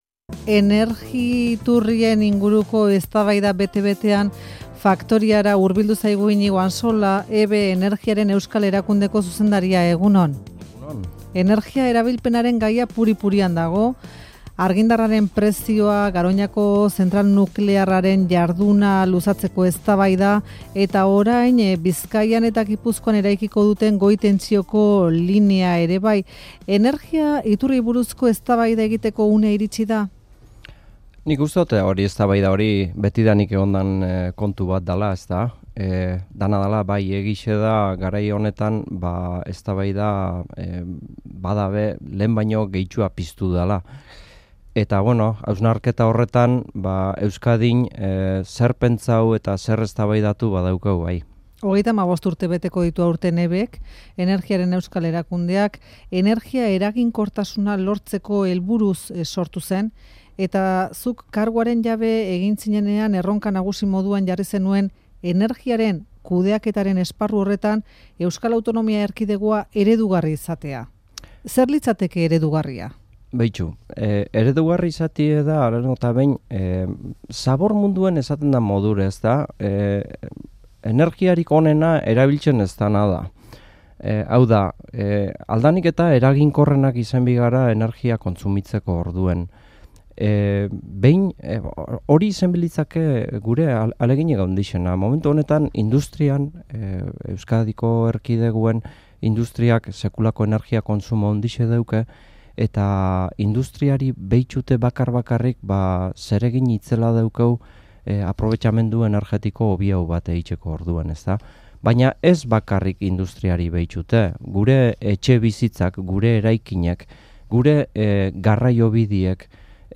Iñigo Ansola Energiaren Euskal Erakundeko zuzendaria Euskadi Irratian